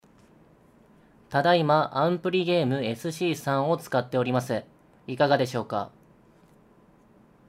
※使用したマイクは「オーディオテクニカ AT2035」です。
AmpliGame SC3の音声